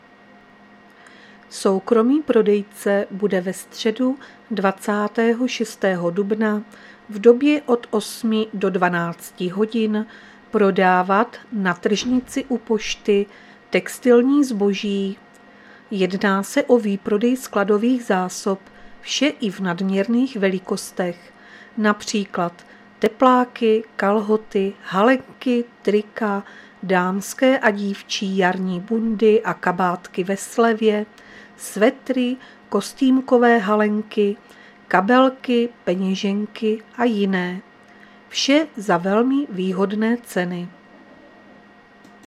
Záznam hlášení místního rozhlasu 25.4.2023